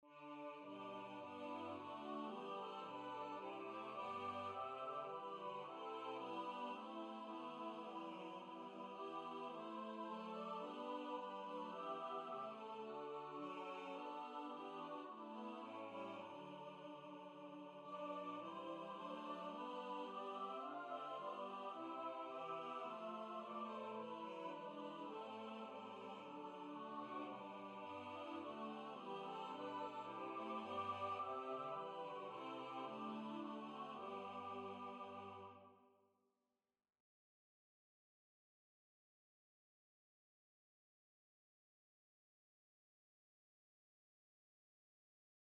Voices: S.A.T.B. Instrumentation: a cappella
NotePerformer 4 mp3 Download/Play Audio